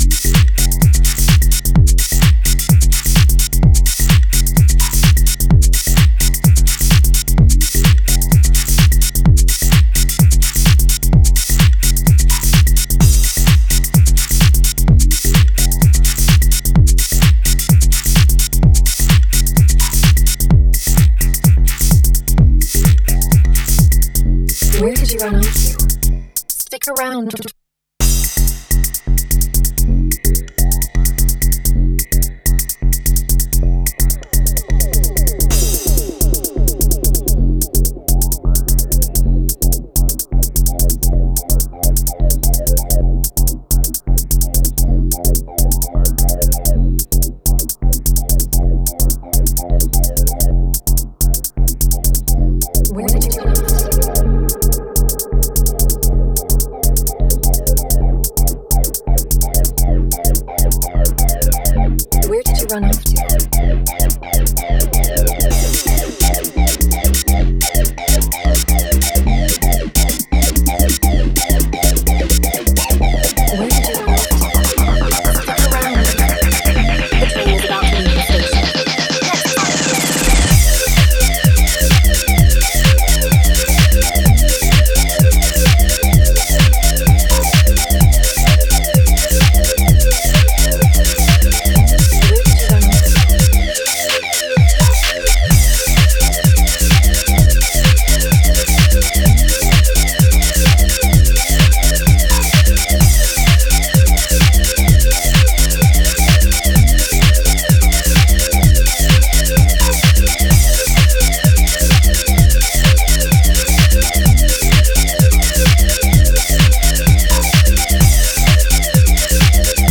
emotionally charged house narratives.